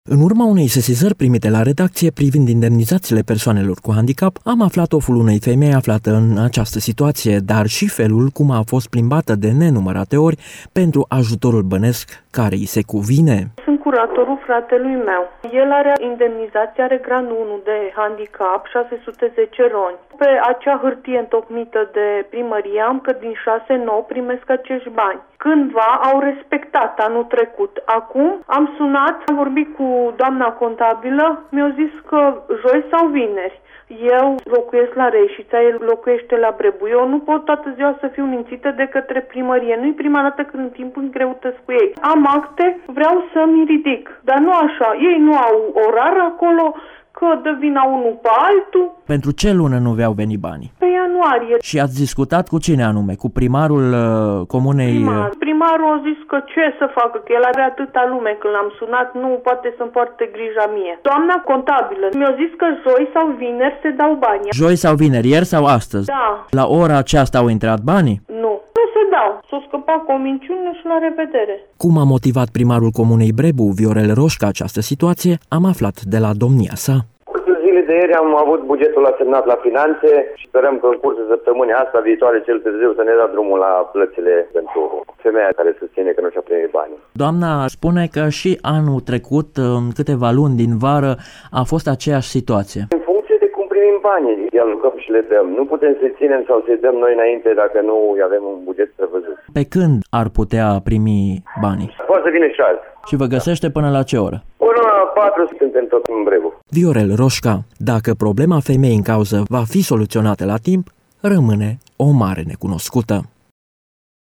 Viorel Roşca, primarul comunei, spune că a făcut tot ce s-a putut pentru a-i ajuta şi că astăzi sau cel târziu luni, 17 februarie sumele de bani rămase restante vor ajunge la beneficiari: “Noi am plătit indemnizaţiile şi ajutorul pentru anul trecut din bugetul local, iar acum încercăm să acoperim diferenţa astfel încât să fie achitate toate ajutoarele restante”.